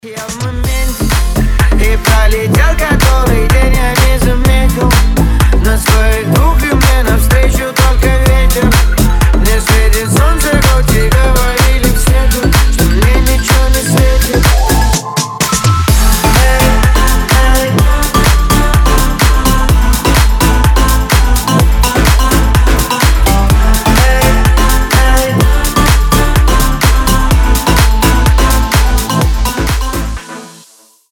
• Качество: 320, Stereo
позитивные
свист
мужской голос
Club House
ремиксы